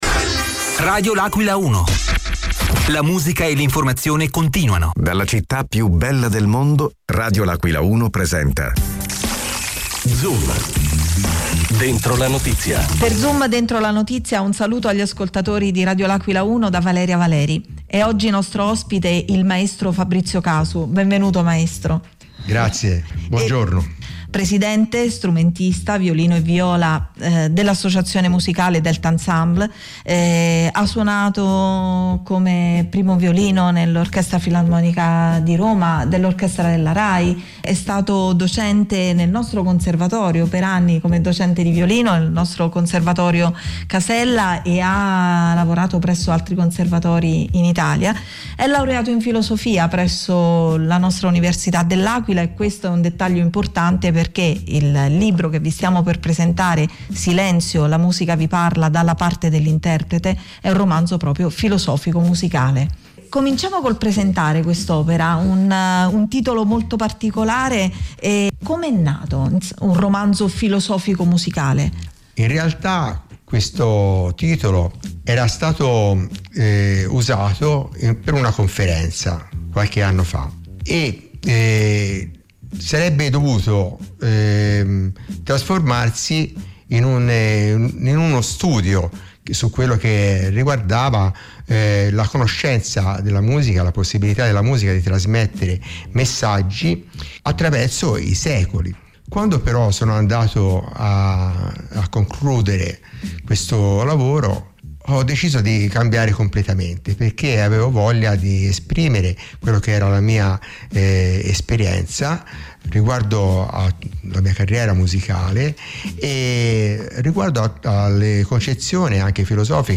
è stato ospite negli studi di Radio L’Aquila 1